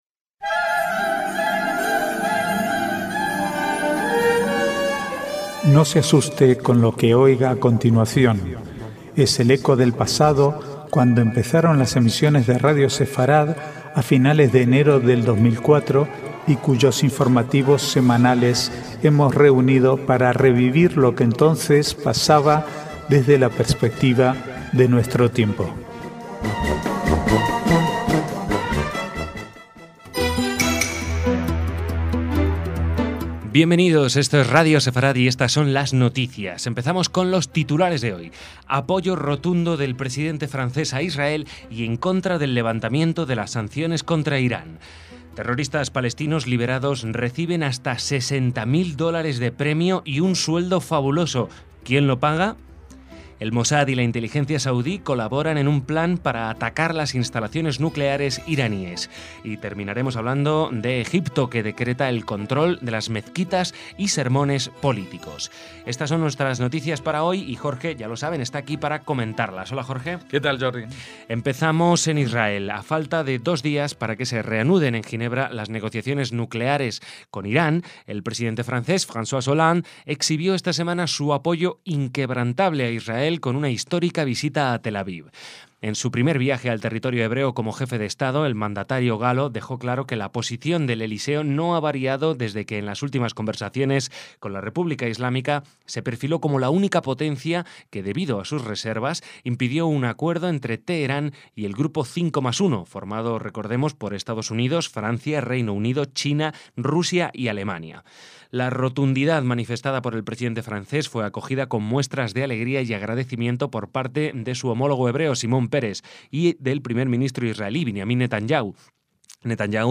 Archivo de noticias del 19 al 22/11/2013